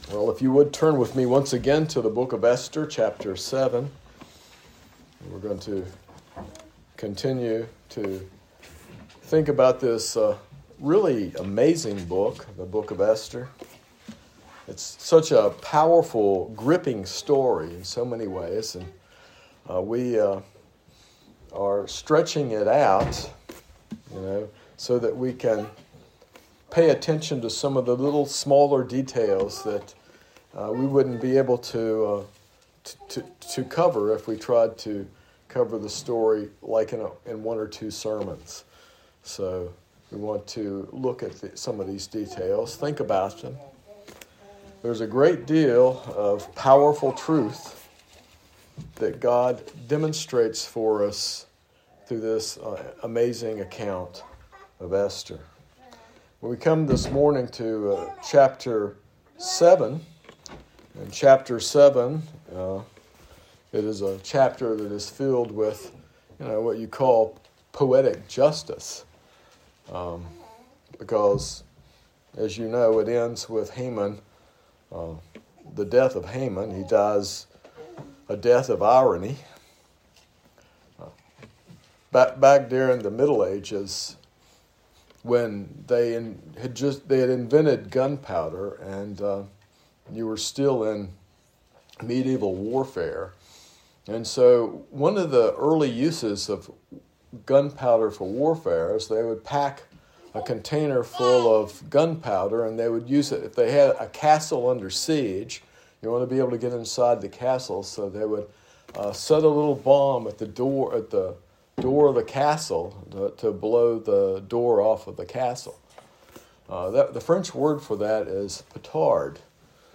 This sermon explores the story of Haman in the book of Esther, focusing on the destructive nature of pride and its consequences. It contrasts Haman’s self-centered life with the humility God desires, highlighting God’s justice and grace.